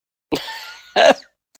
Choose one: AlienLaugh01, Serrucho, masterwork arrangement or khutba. AlienLaugh01